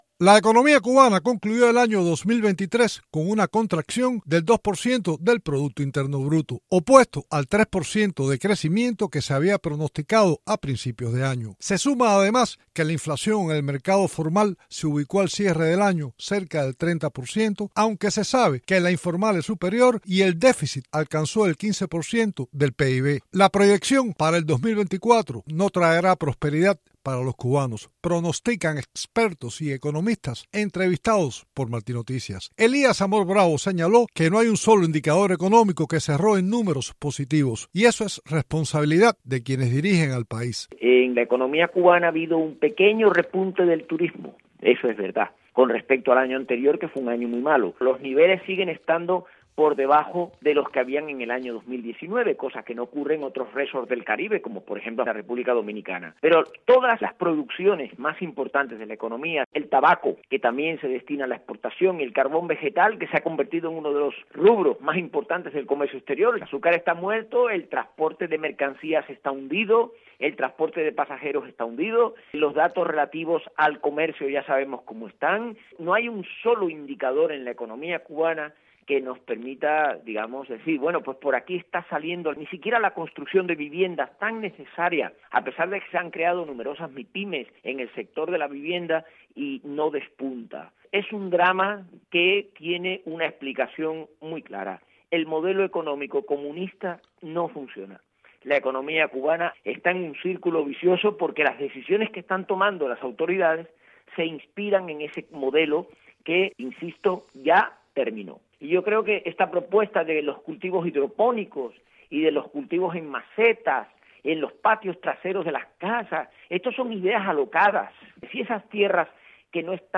Sin embargo, economistas y expertos, entrevistados por Martí Noticias creen que el 2024 no traerá la anhelada prosperidad para los cubanos.